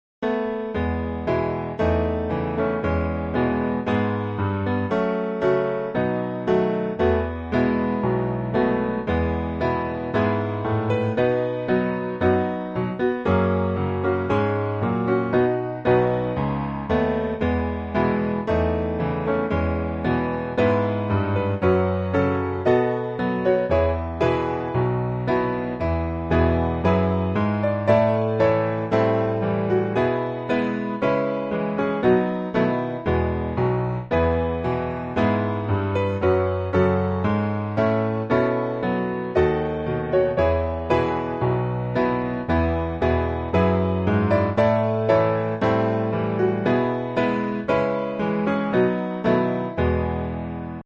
E Majeur